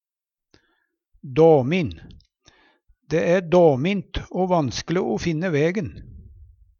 dåmin - Numedalsmål (en-US)
Hør på dette ordet Ordklasse: Adjektiv Attende til søk